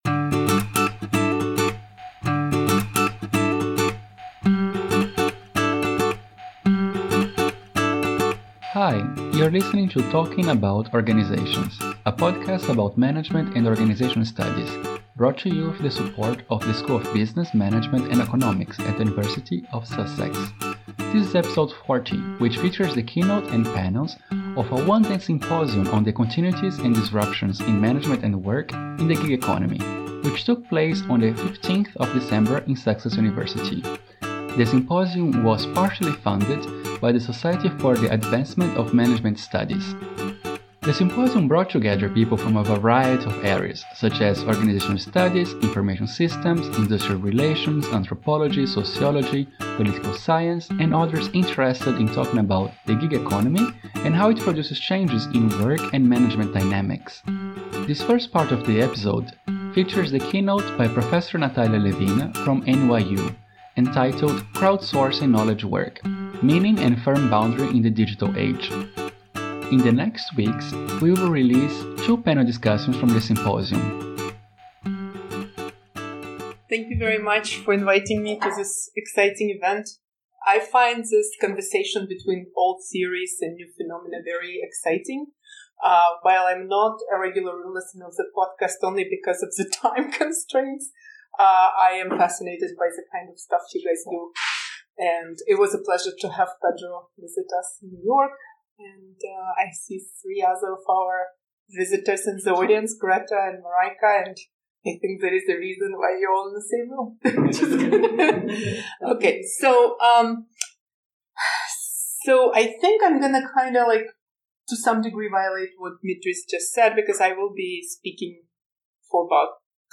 40: Symposium on the Gig Economy LIVE - Talking About Organizations Podcast
A SPECIAL EPISODE FROM OUR VERY FIRST EVENT!
The TAOP Symposium on the Gig Economy was a unique, one-day interdisciplinary symposium on the forms and effects of management in the contemporary sharing (a.k.a. gig) economy that took place on 15 December 2017 at the University of Sussex. Blending individual and panel presentations from leading scholars and commentators with group conversations, we wanted to examine the continuities – as well as disruptions – in the ways that work is organised through, and in light of, online platforms such as uber, deliveroo, upwork.